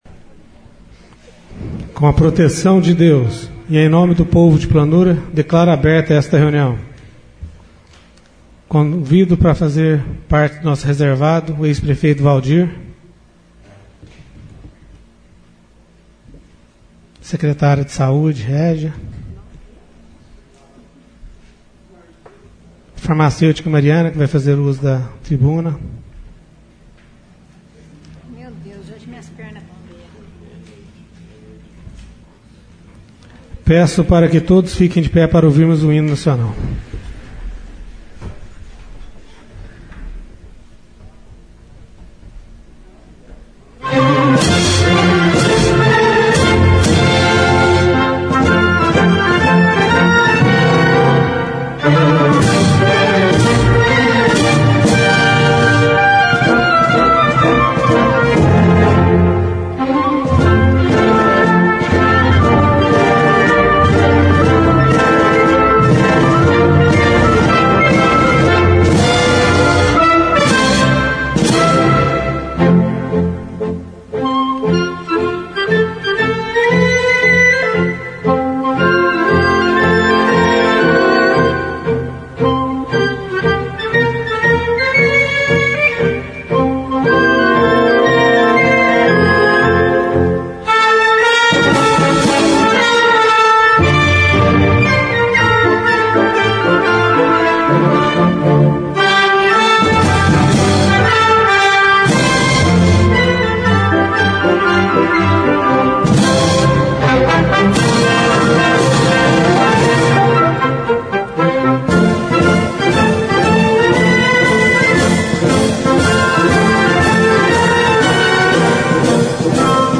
Sessão Ordinária - 18/11/13 — CÂMARA MUNICIPAL DE PLANURA